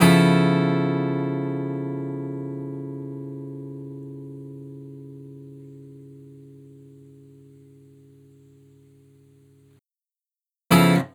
06 Jazzy Two.wav